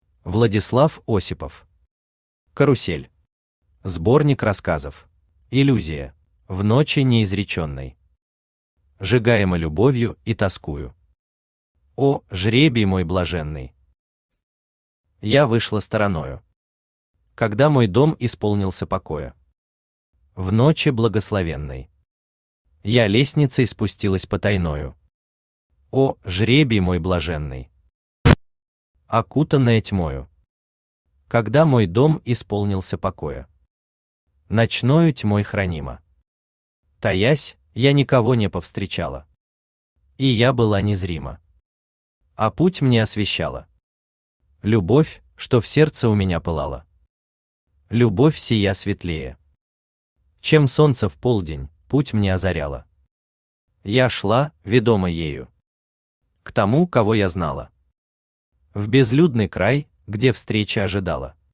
Аудиокнига Карусель. Сборник рассказов | Библиотека аудиокниг